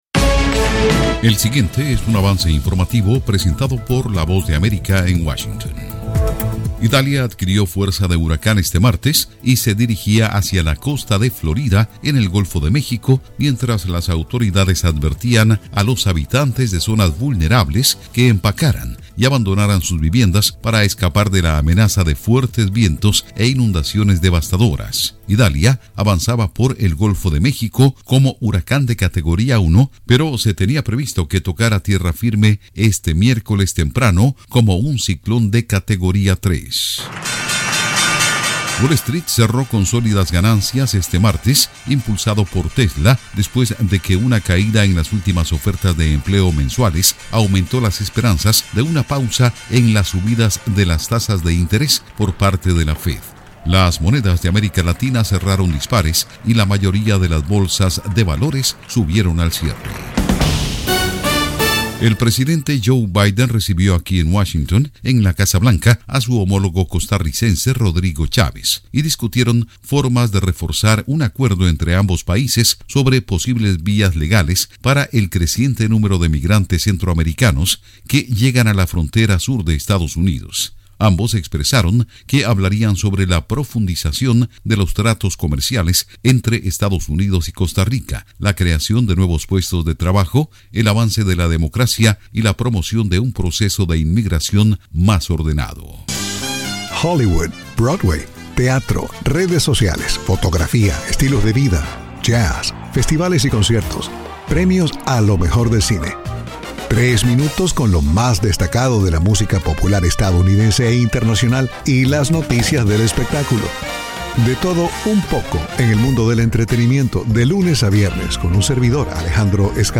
El siguiente es un avance informativo presentado por la Voz de América en Washington